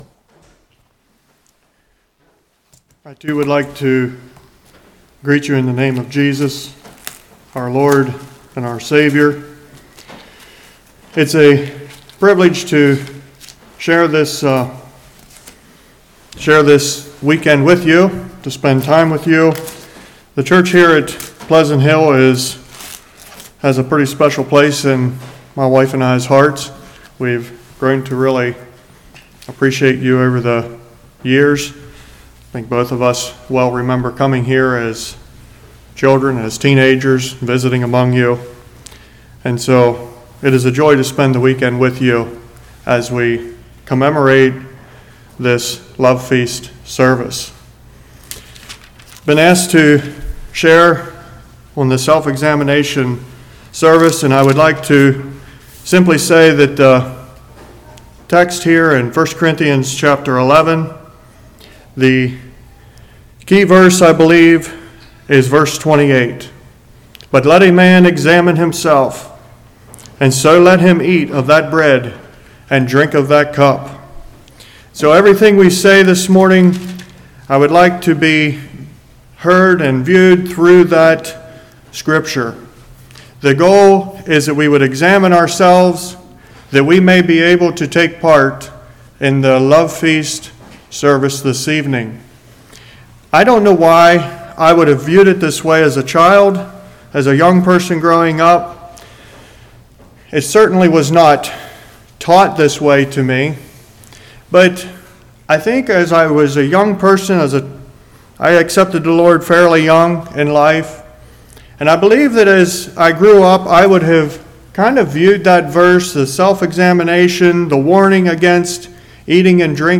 Service Type: Love Feast